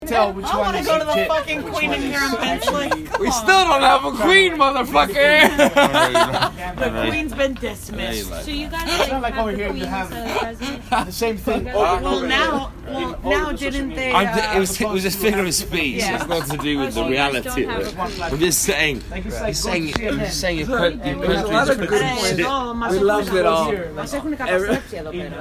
Street corner at 2am, Washington DC
Recorded entirely on an iPhone 6s, the album is designed to be listened to in a single sitting, it’s 23 tracks blending continuously to unfurl the story of a unique journey around the world.